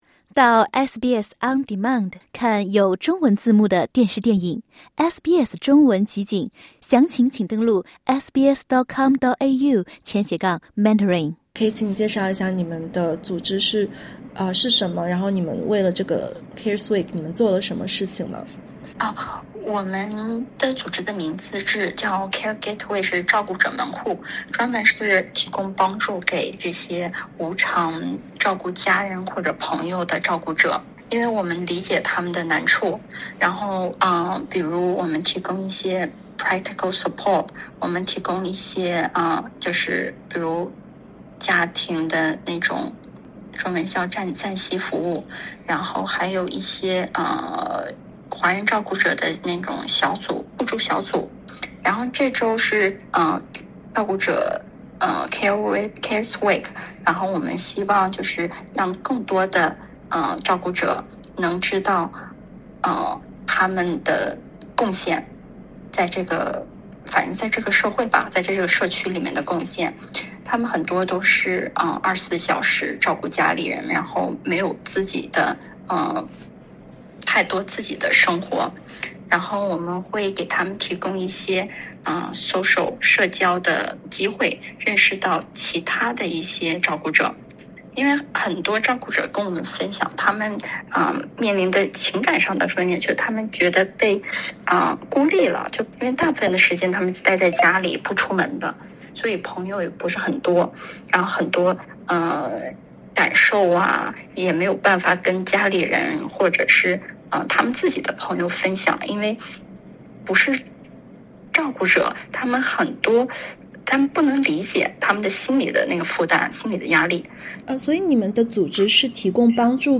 许多人全年无休地照料他人，却忽视了自身的孤独与压力（点击音频，收听详细报道）。